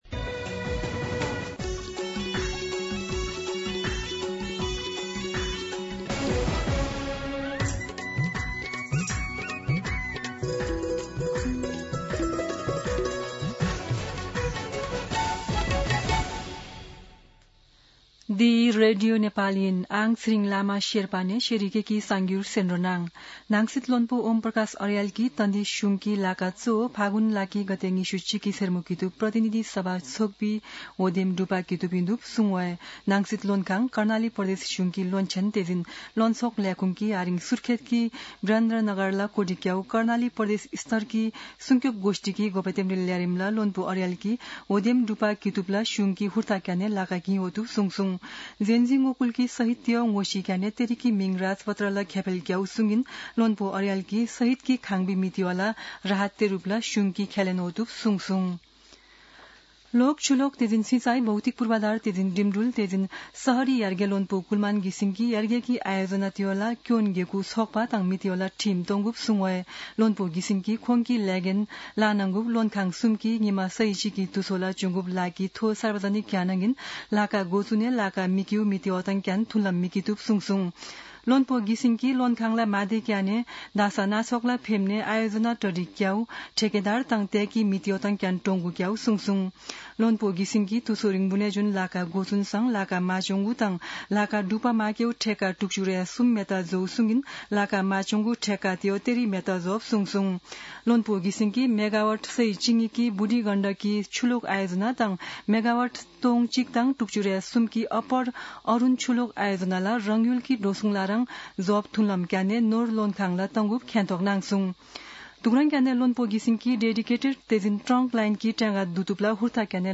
शेर्पा भाषाको समाचार : ९ पुष , २०८२
Sherpa-News-1.mp3